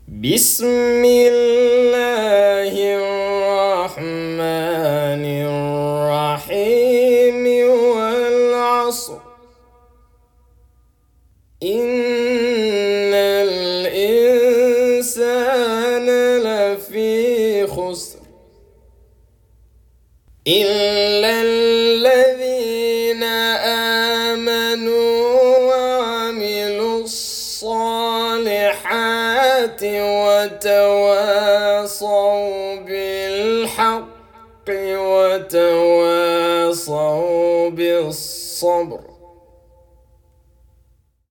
알 아스르(Al-Asr) in 무자와드